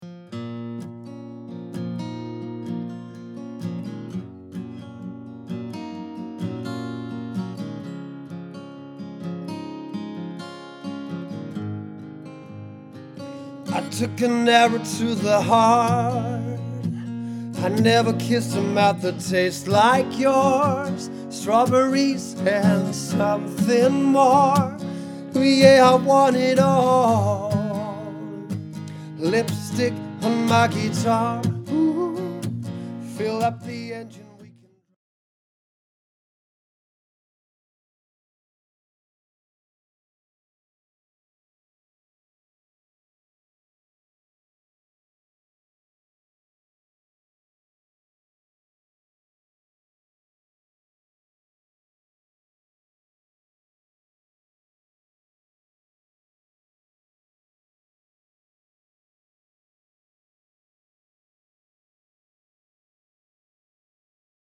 Jeg spiller guitar og synger, og det er det bedste jeg ved.